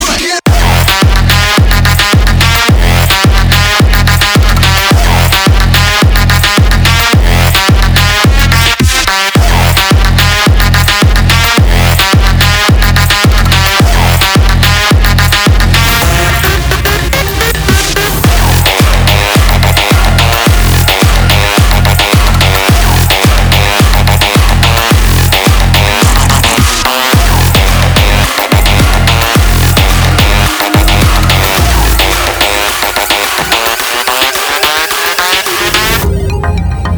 Trap-Bass Ура!